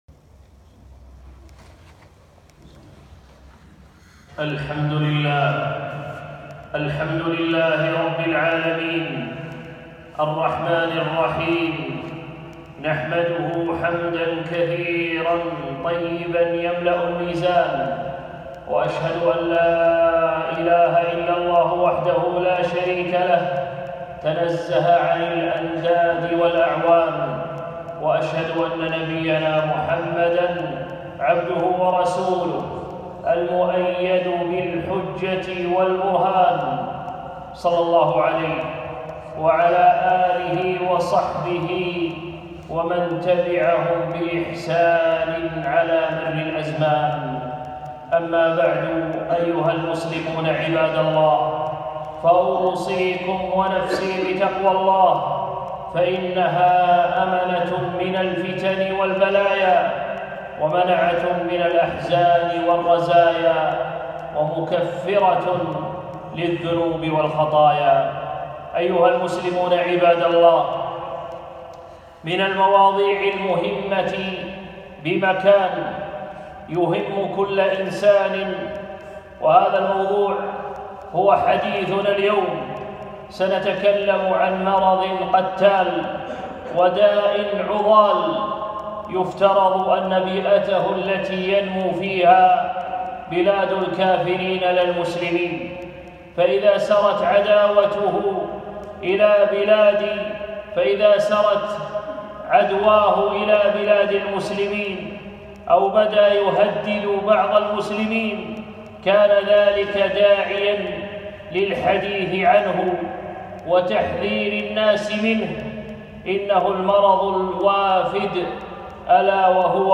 خطبة - مـرض خطيـر